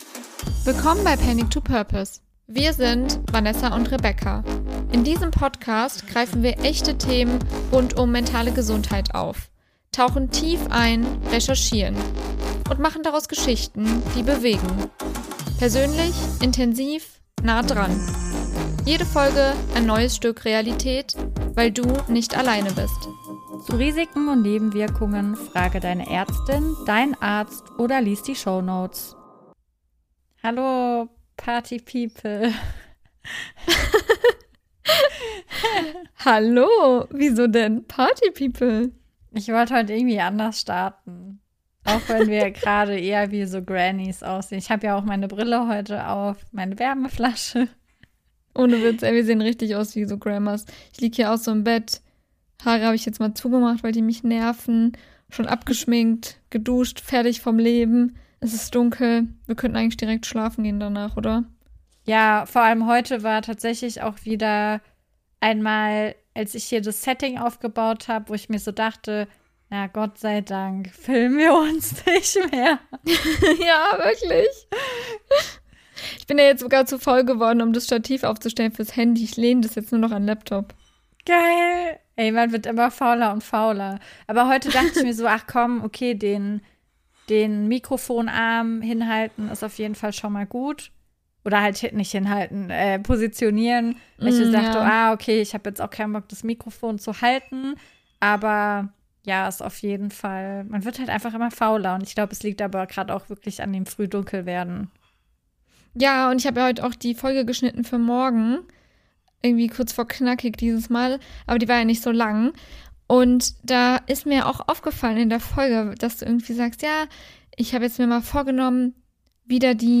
Du hörst eine packende, erzählerische Ich-Perspektive – wie ein True-Crime-Hörbuch, nur emotional.